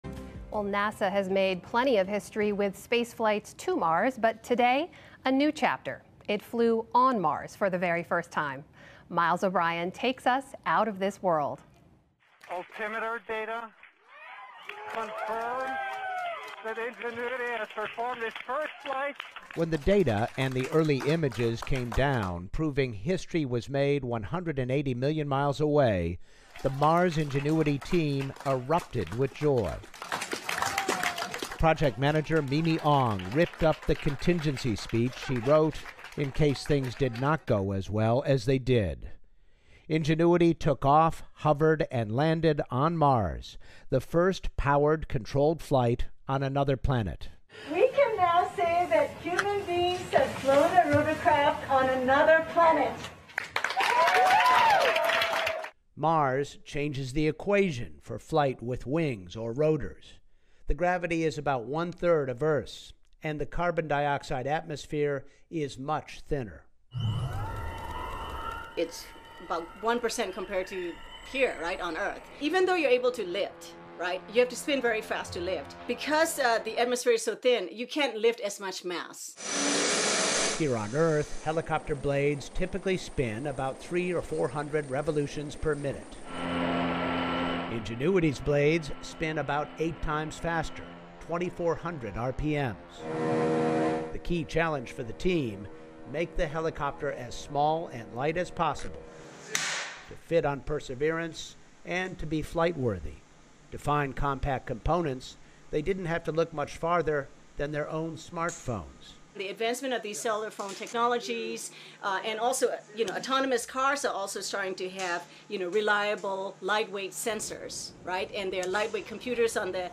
英语访谈节目:美国"机智号"火星直升机首飞成功